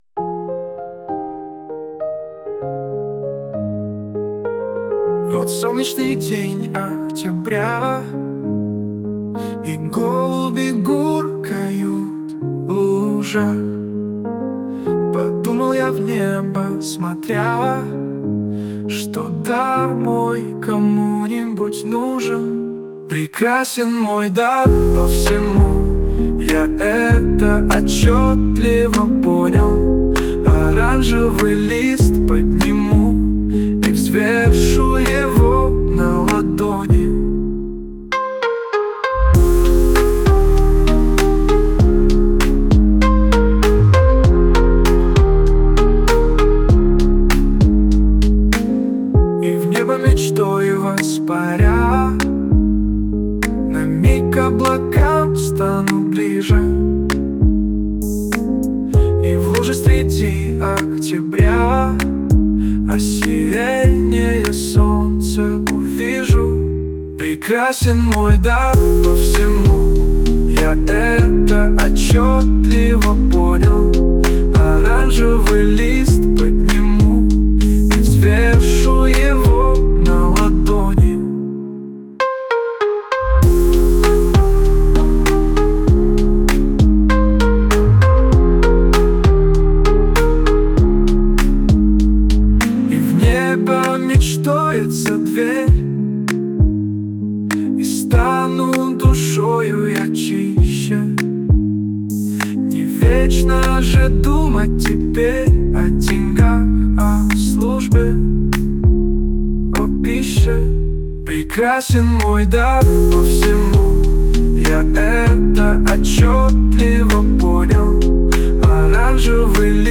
Синтвейв